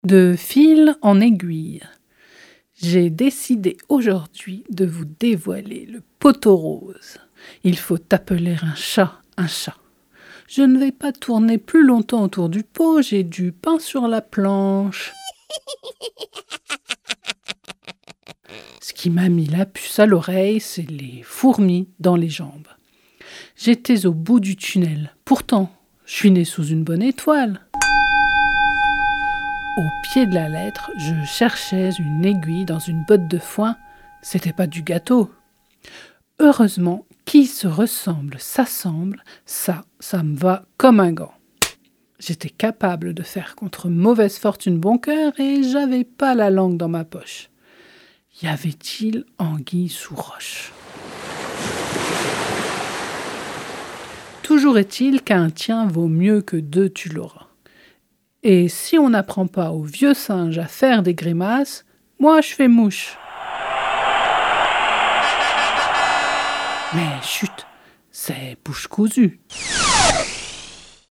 🎧 De fil en aiguille - Les ateliers de fictions radiophoniques de Radio Primitive